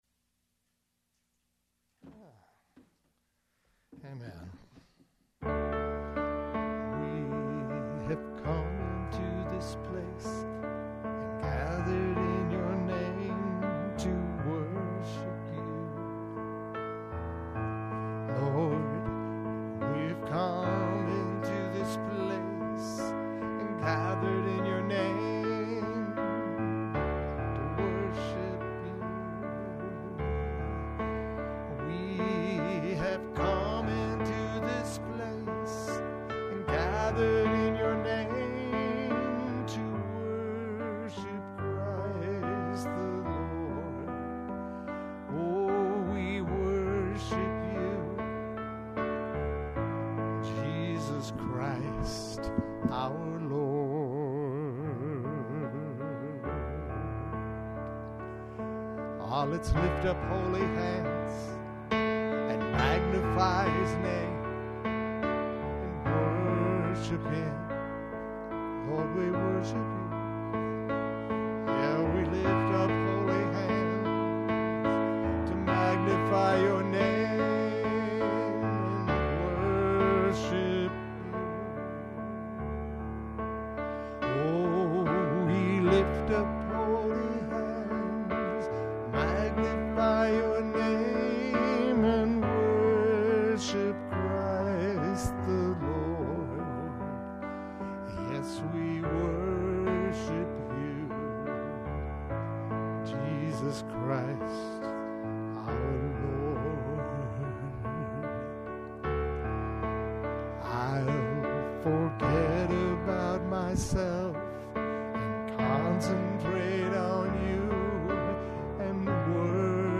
WORSHIP 1019.mp3